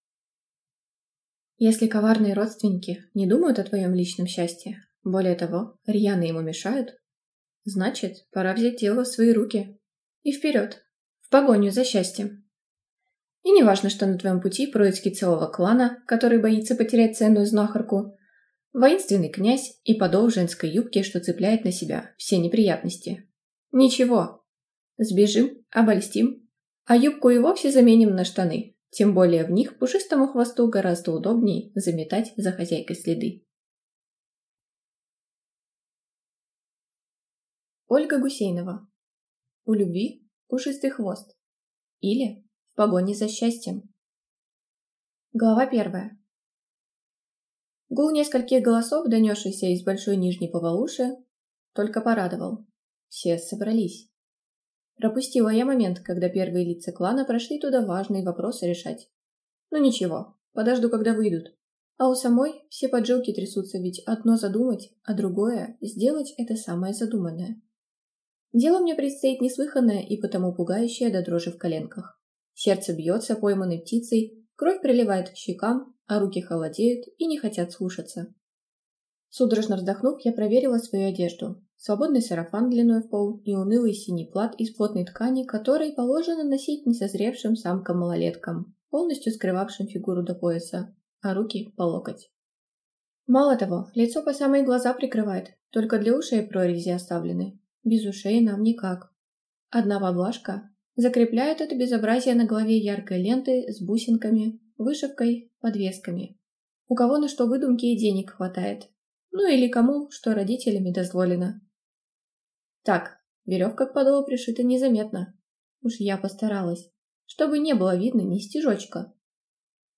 Аудиокнига У любви пушистый хвост, или В погоне за счастьем - купить, скачать и слушать онлайн | КнигоПоиск